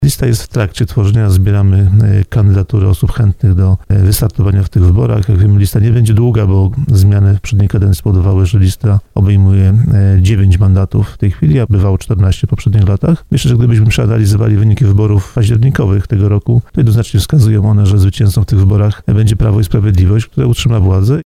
Przed wyborami, lista kandydatów do sejmiku jest w trakcie tworzenia. Wojciech Skruch powiedział na naszej antenie, że będzie się starał o mandat radnego.